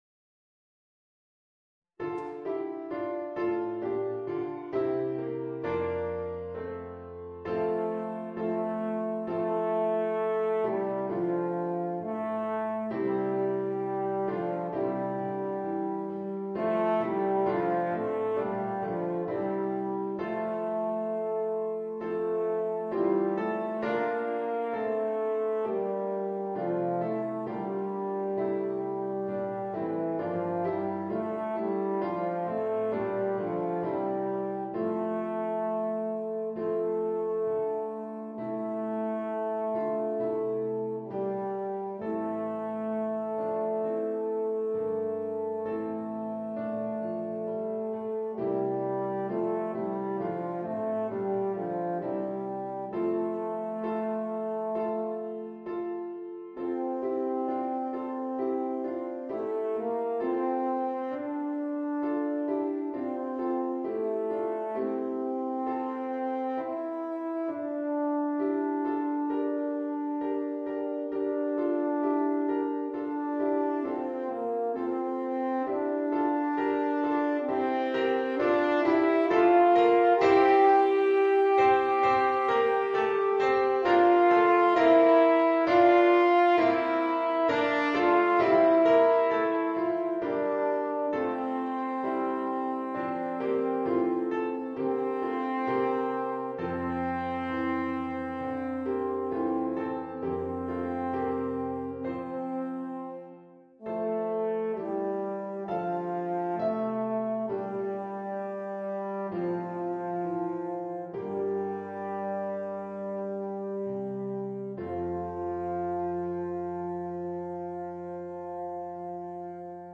Horn & Klavier